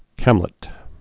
(kămlĭt)